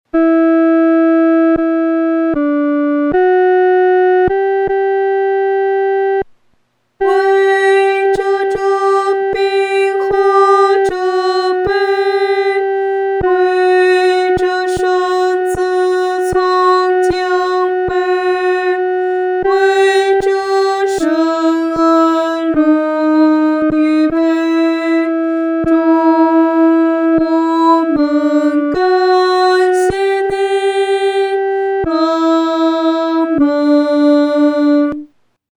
女高 下载